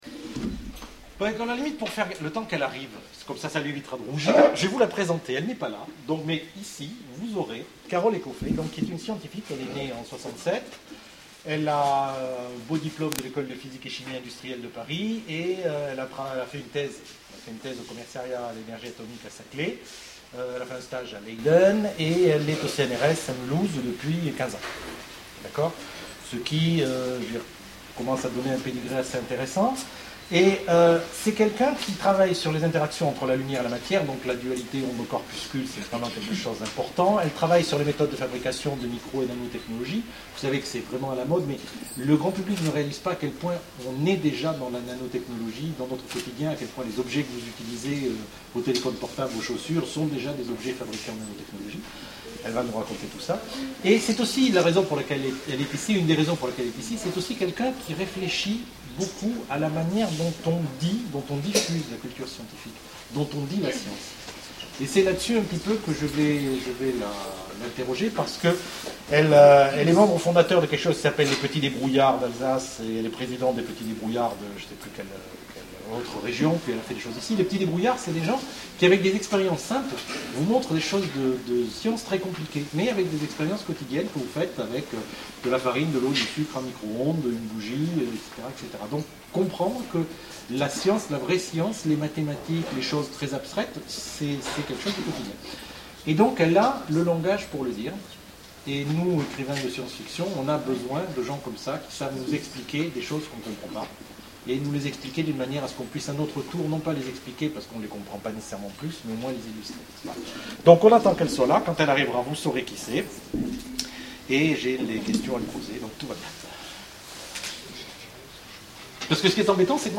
Imaginales 2011 : Conférence Science et science fiction, le regard d'une scientifique